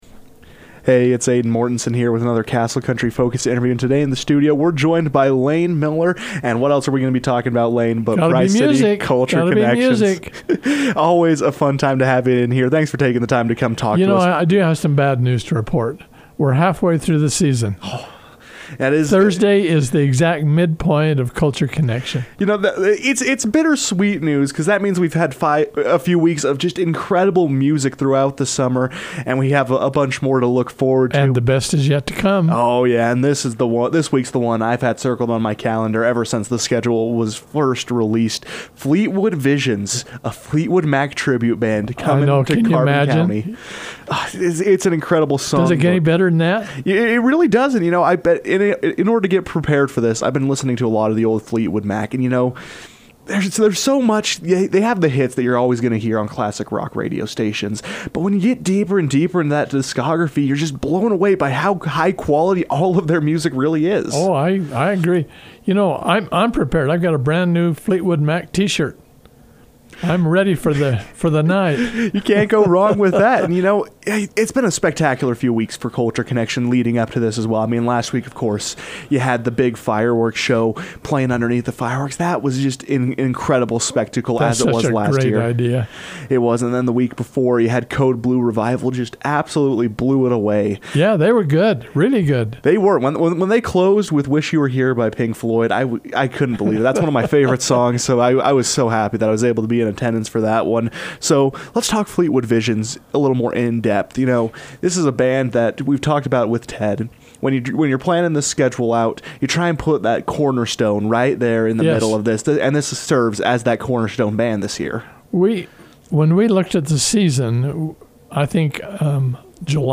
Price City Council Member Layne Miller joined the KOAL newsroom to preview the affair and reflect on the Culture Connection season thus far.